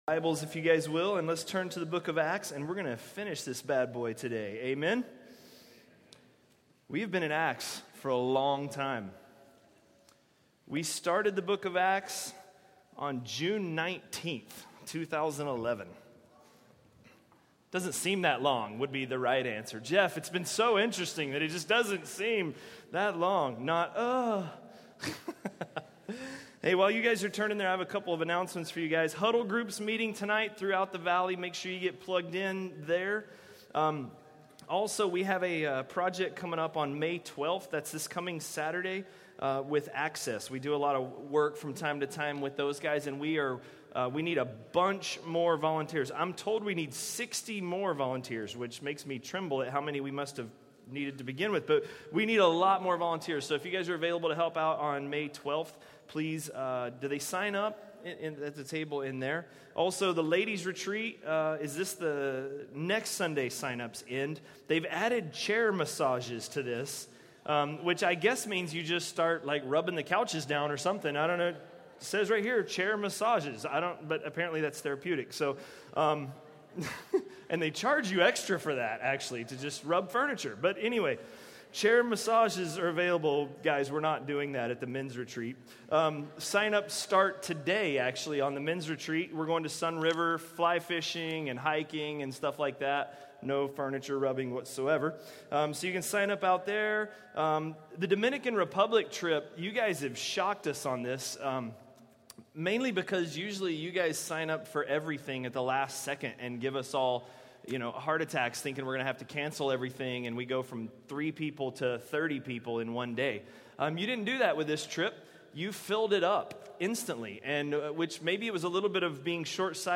A message from the series "Acts."